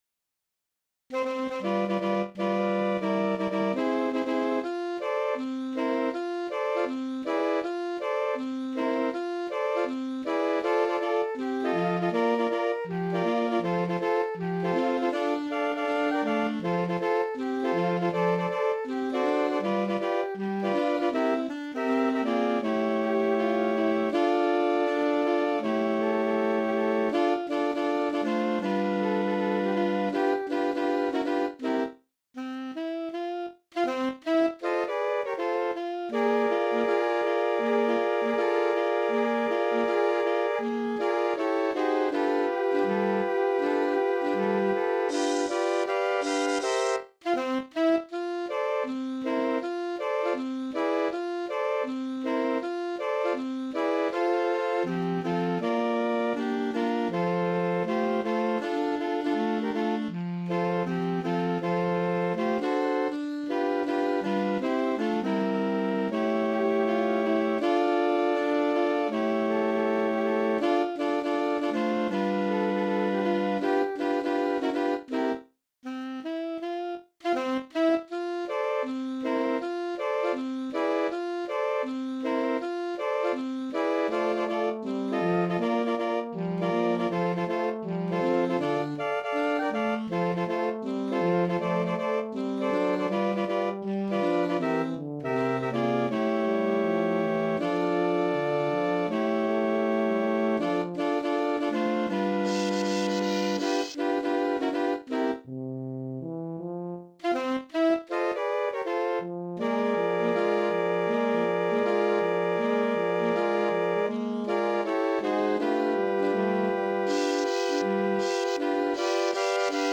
This arrangement is for four upper voices and two soloists.